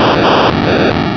pokeemerald / sound / direct_sound_samples / cries / voltorb.aif